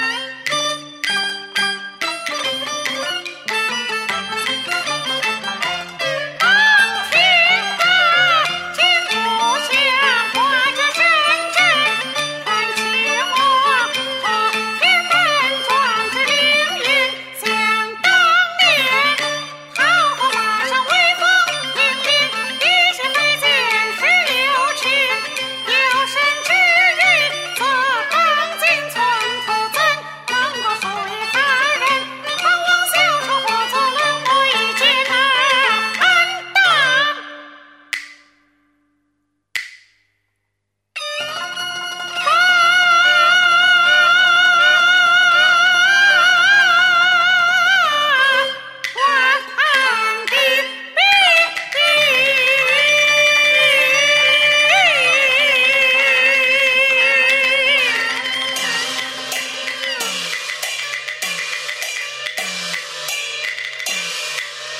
京剧